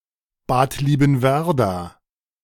Bad Liebenwerda (German: [baːt ˌliːbm̩ˈvɛʁda]